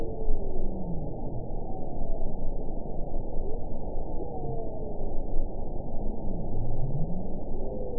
event 922418 date 12/31/24 time 21:27:35 GMT (11 months ago) score 9.20 location TSS-AB06 detected by nrw target species NRW annotations +NRW Spectrogram: Frequency (kHz) vs. Time (s) audio not available .wav